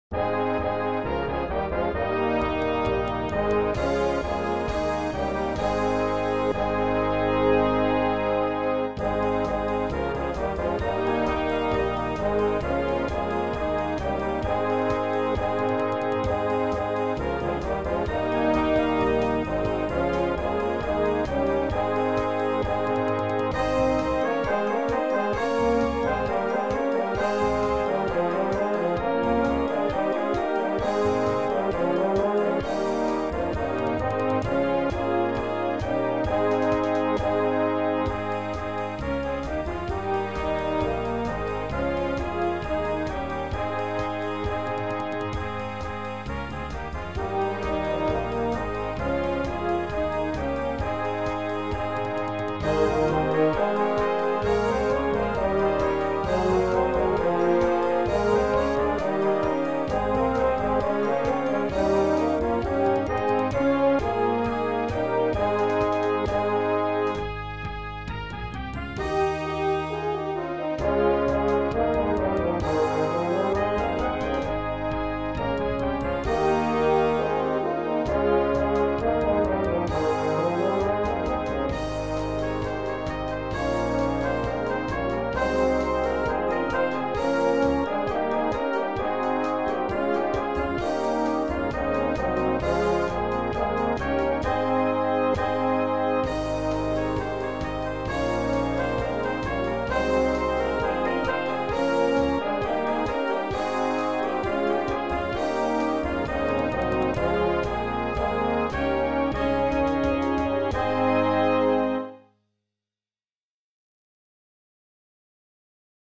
82B1 Brass Band $20.00 **
(computer generated sound sample)